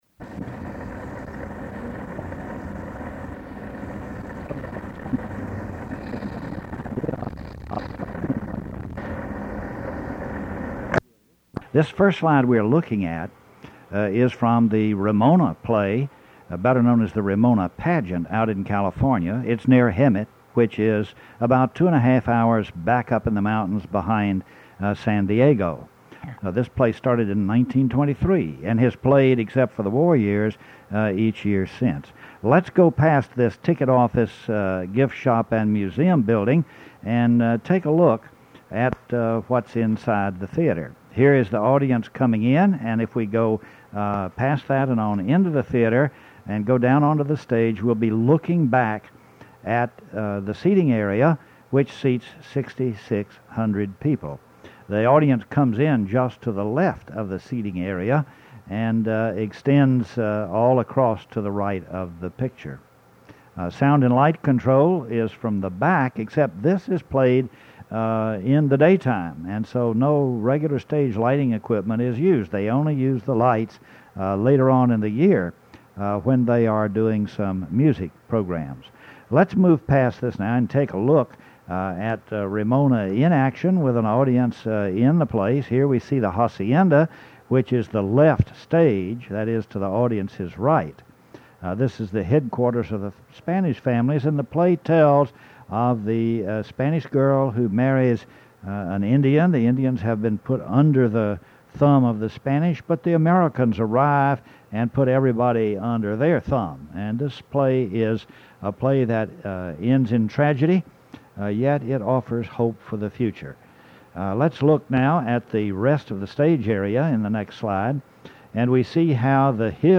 Slide lecture - ECU Digital Collections